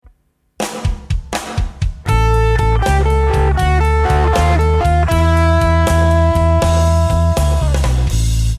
Walking Up & Down With Root Note Pedal Lick
These walking up and down licks can be varied by adding the root note as a pedal tone throughout. To emphasise this pedal tone note the lick can be played with triplets.
blues_turnaround_lick03.mp3